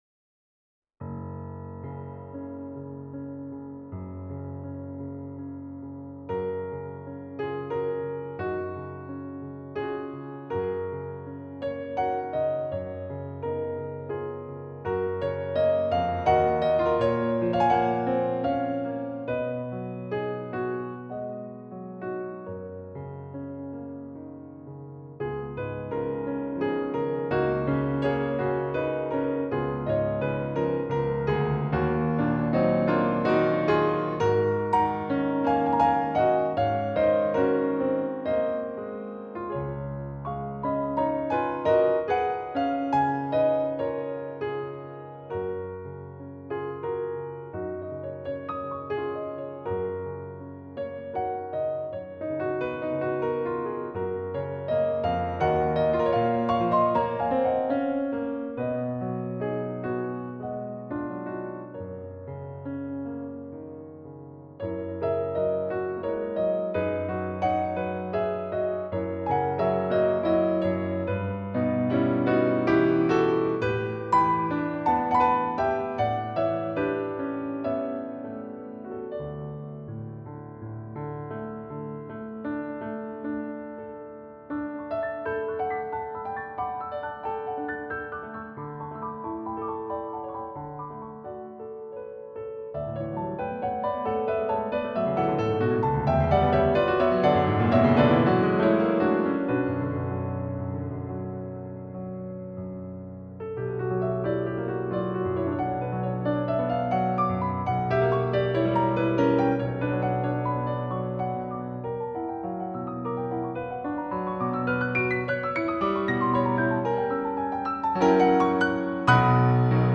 一つのMIDIデータからいろいろな発音音源で再生させてMP3に録音したサンプルです。
Ivory II Steinway D9 (MP3)Galaxy Vintage D Steinway D-274 (MP3)作曲家メモ・・・